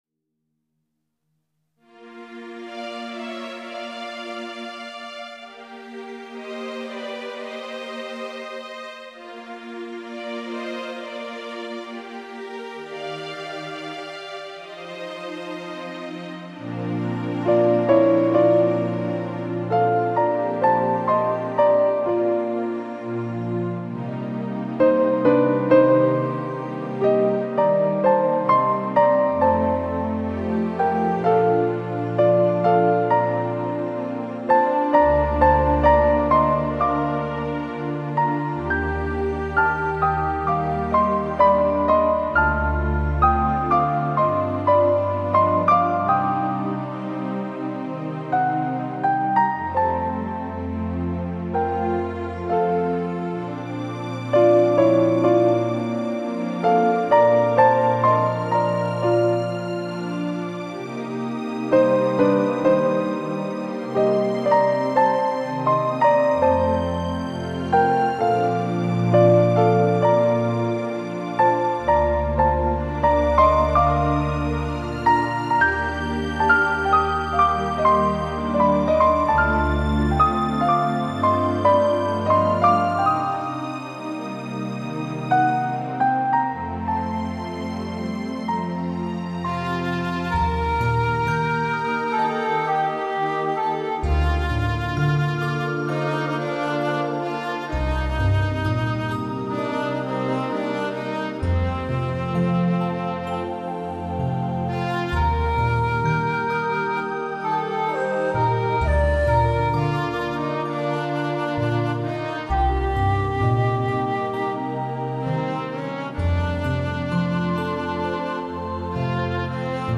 Music for relaxation and reflection
piano